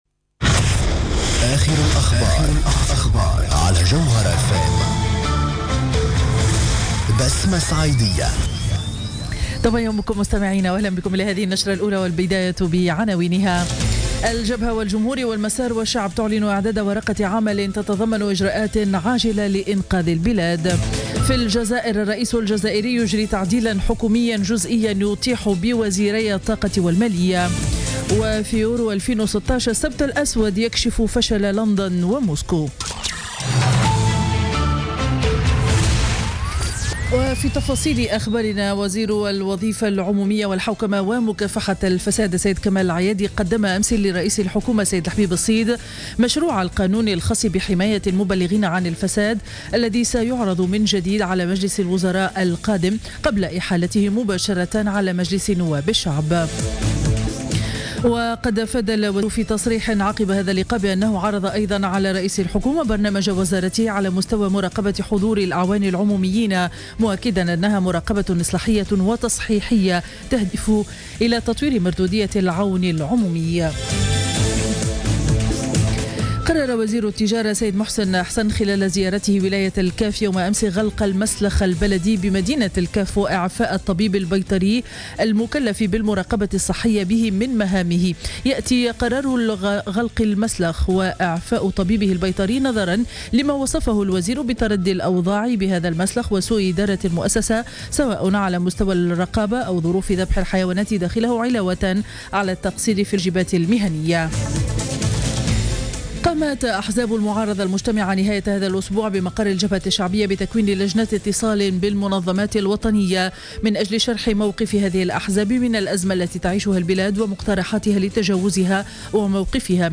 Journal Info 07h00 du Dimanche 12 Juin 2016